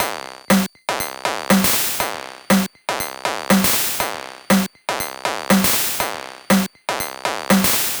120_BPM
ChipShop_120_Drums_08.wav